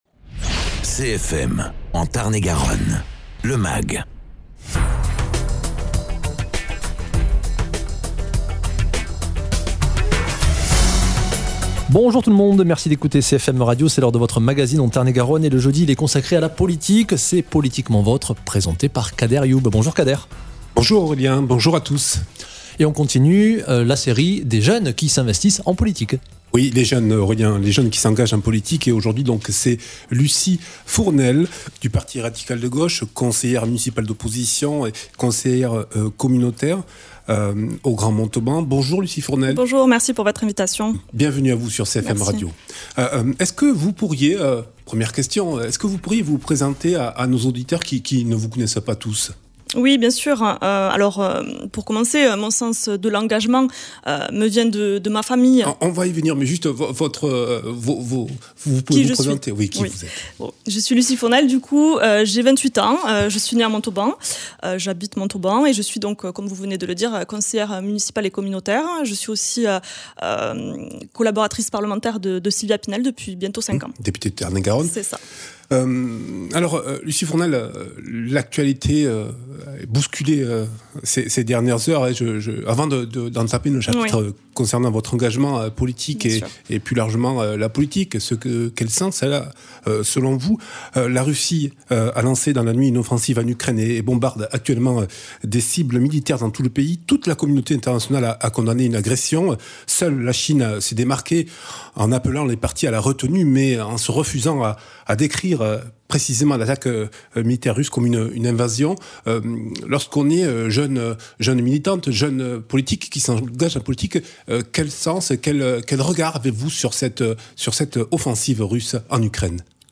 Lucie Fournel, (PRG) conseillère municipale d’opposition à Montauban était l’invitée politiquement votre. L’occasion d’aborder la crise en Ukraine, la jeunesse et l’engagement politique, la stratégie du PRG pour la présidentielle, les relations au sein du conseil municipal de Montauban.